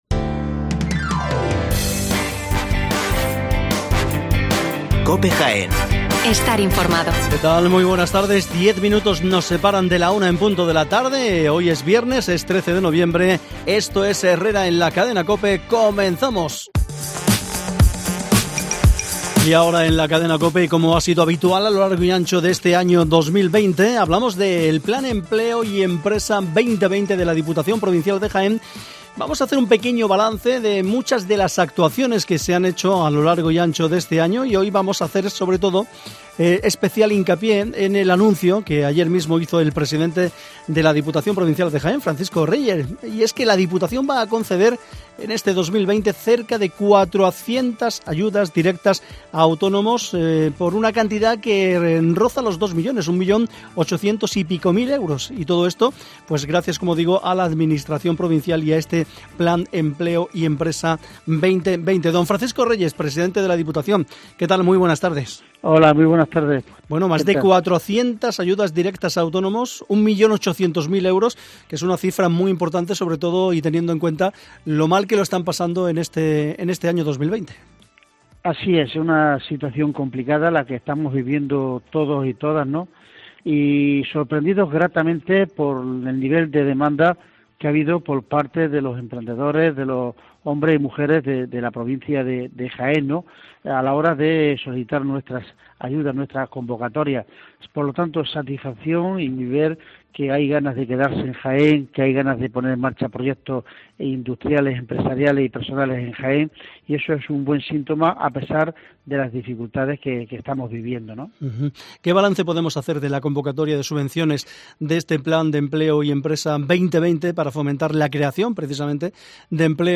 ENTREVISTA MEDIODÍA COPE